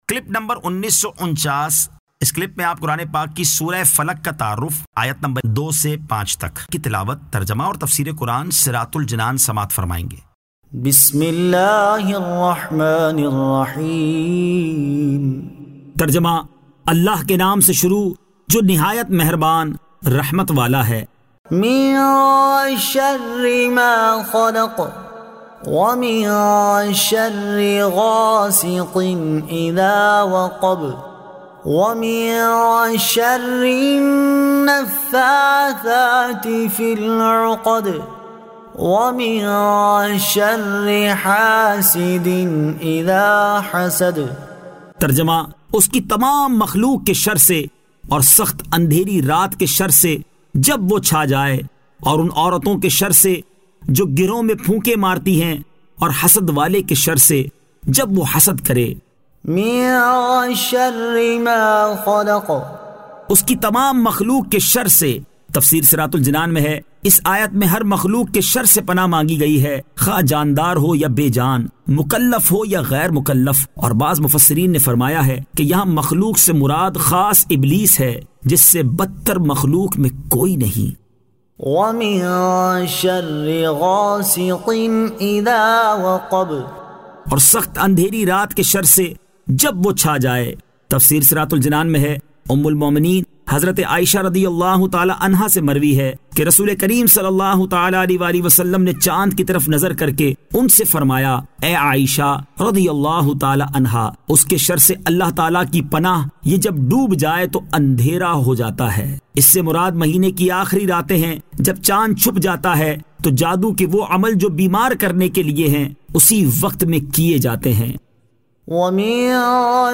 Surah Al-Falaq 02 To 05 Tilawat , Tarjama , Tafseer